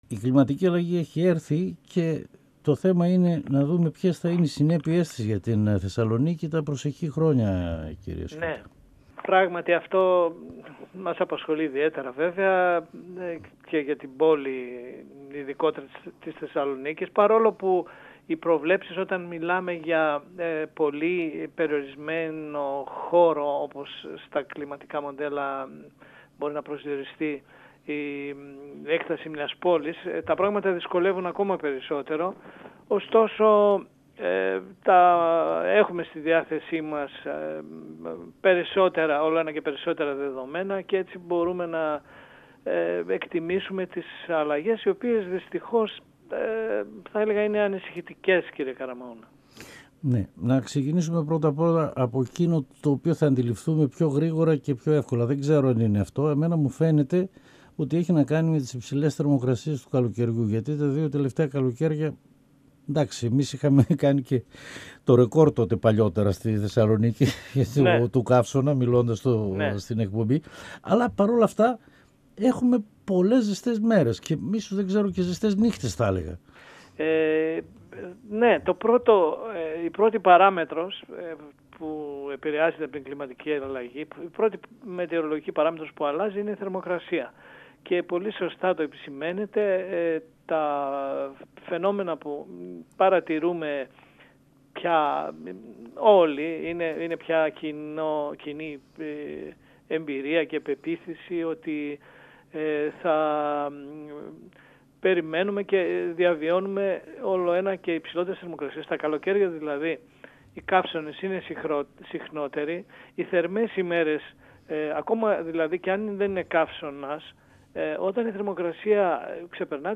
Εδω και Τωρα Συνεντεύξεις